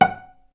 vc_pz-F#5-ff.AIF